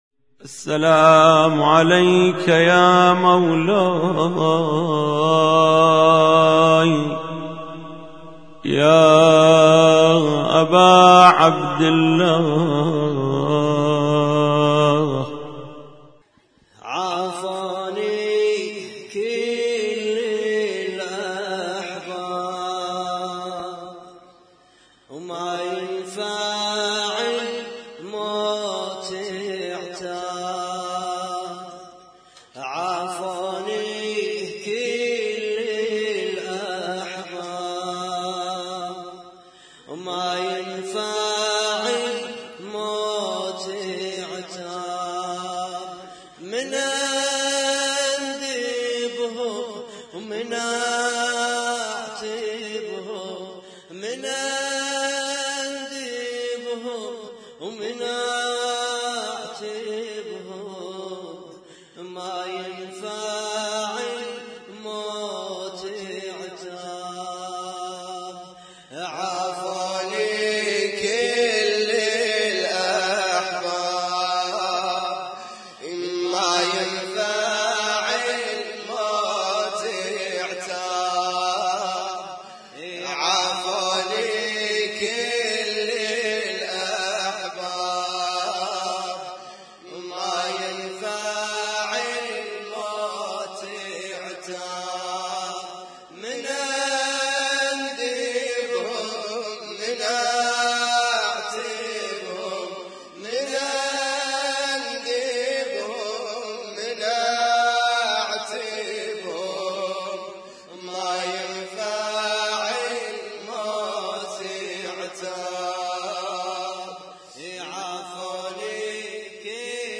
اسم النشيد:: لطم مشترك - ليلة 6 محرم 1436